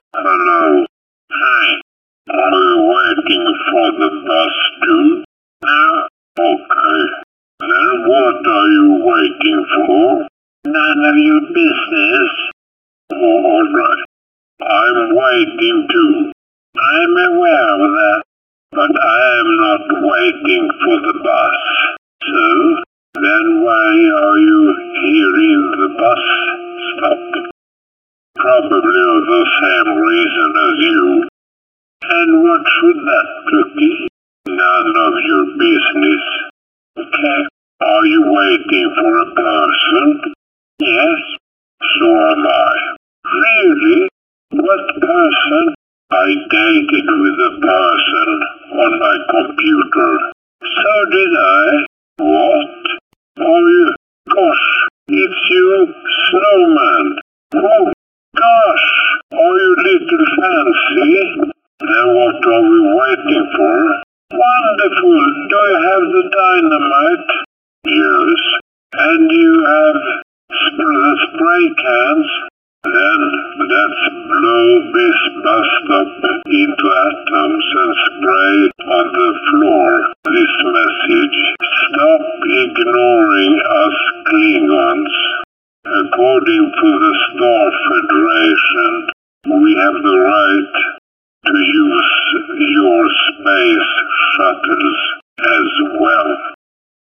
描述：可能在地球上的某个小戏剧。失望的克林顿不容忽视。他们脾气暴躁。
标签： 克林贡 联邦 消息 抗议